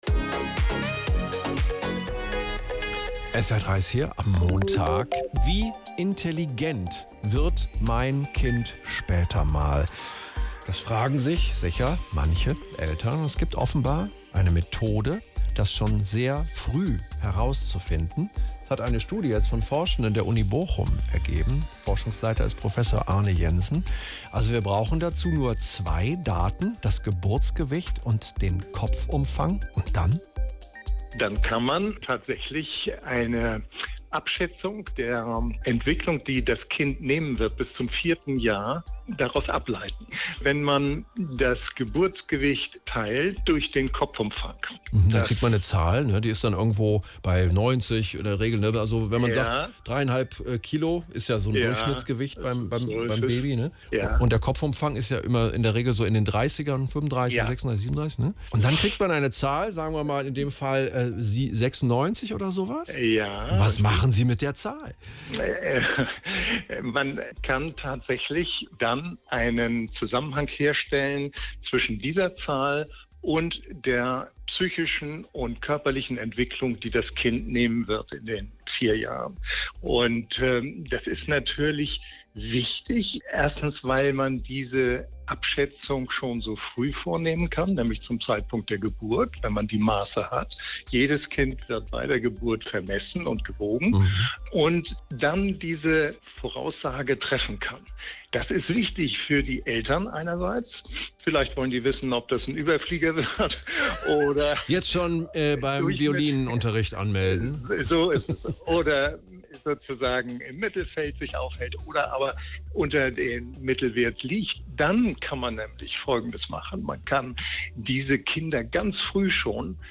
SWR3-Interview vom 05.06.2023